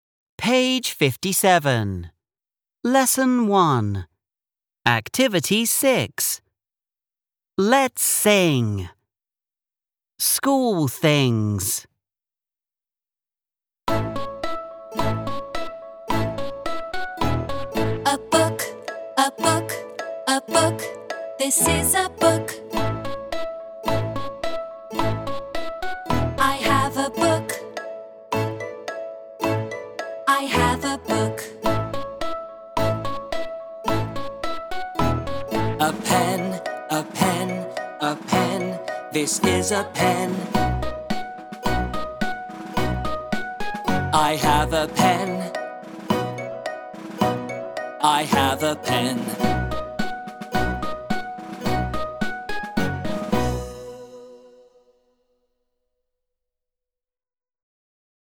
6. Let’s sing
(Hãy hát)